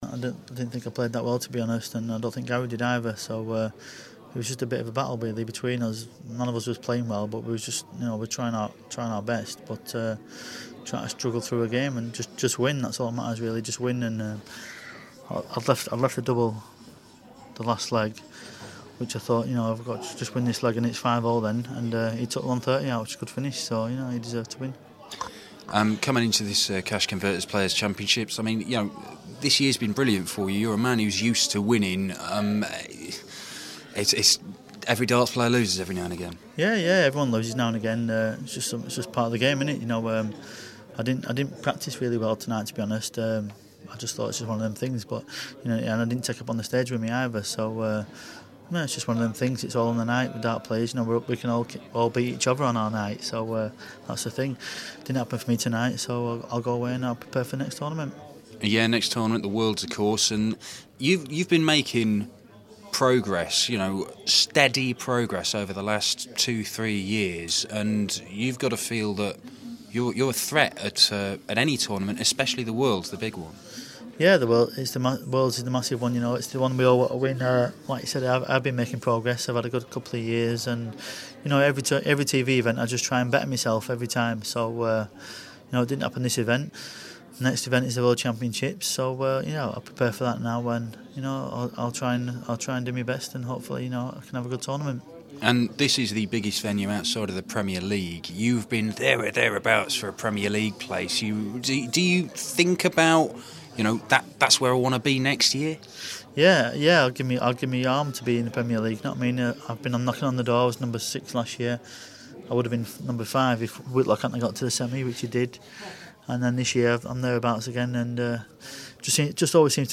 Wes Newton following his 6-4 first round defeat to Gary Anderson at the Cash Converters Players Championship.